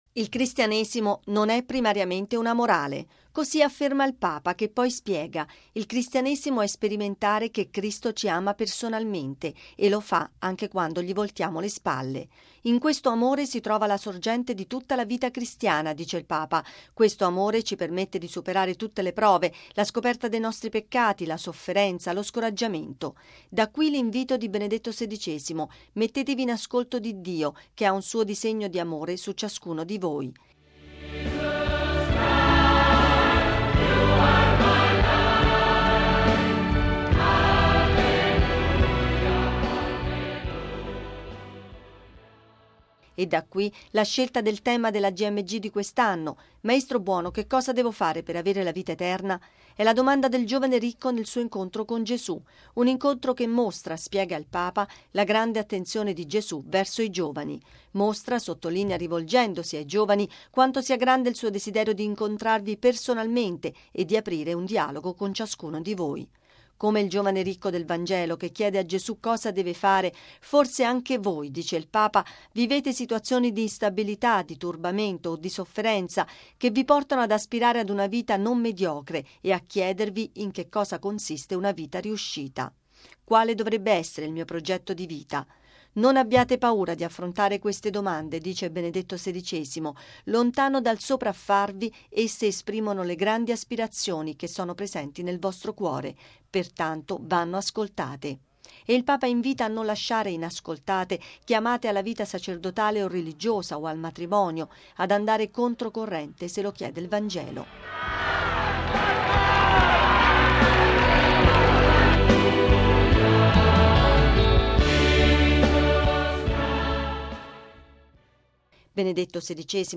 (Musica)